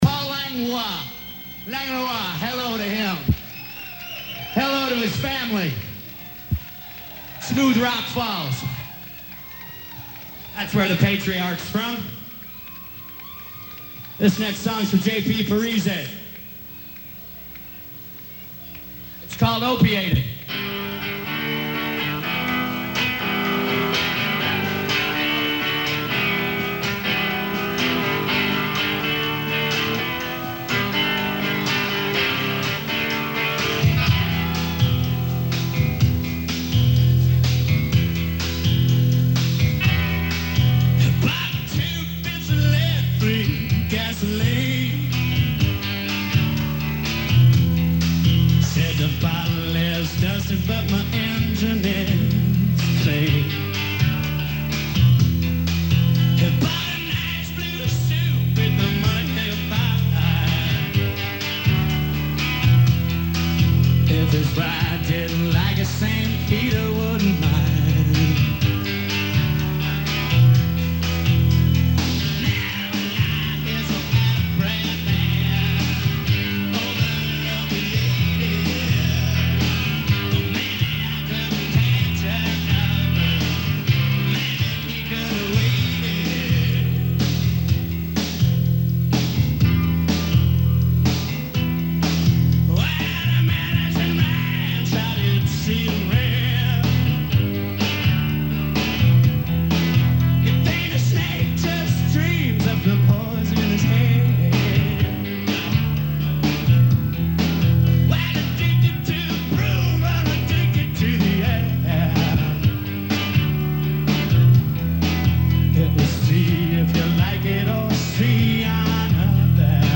Live In 1988